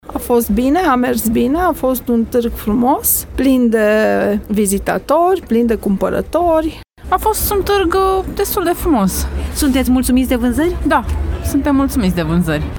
Comercianții se declară mulțumiți de cum au mers vânzarile până acum, la această ediție a Târgului de Crăciun:
VOX-targ-craciun-brasov-2.mp3